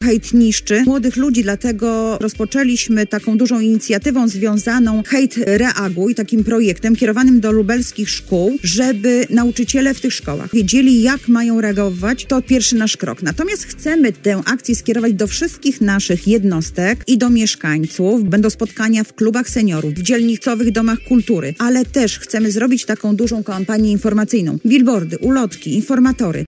O inicjatywie dla lubelskiej młodzieży, jej rodziców i innych mieszkańców „Hejt – Reaguj” mówiła wiceprezydent Lublina Anna Augustyniak w programie „Druga połowa dnia” w Radiu Lublin.